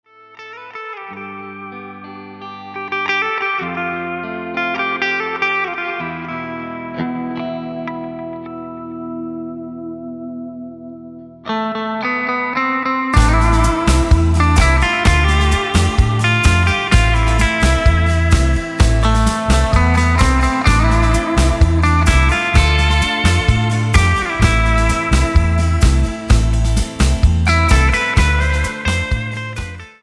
Tonart:Am Multifile (kein Sofortdownload.
Die besten Playbacks Instrumentals und Karaoke Versionen .